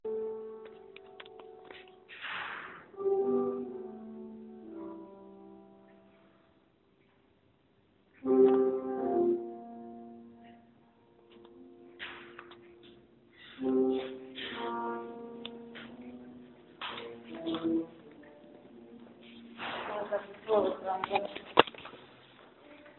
Descarga de Sonidos mp3 Gratis: ambiente 5.
sobre-el-terreno-de-grabacion_22.mp3